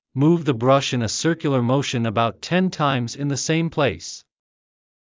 ﾑｰﾌﾞ ｻﾞ ﾌﾞﾗｯｼ ｲﾝ ｱ ｻｰｷｭﾗｰ ﾓｰｼｮﾝ ｱﾊﾞｳﾄ ﾃﾝ ﾀｲﾑｽﾞ ｲﾝ ｻﾞ ｾｲﾑ ﾌﾟﾗｲｽ